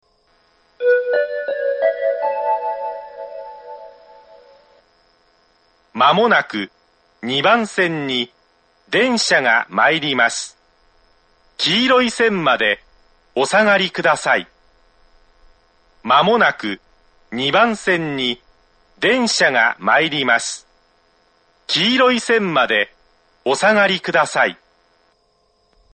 ２番線接近放送
２番線発車メロディー 曲は「Gota del Vient」です。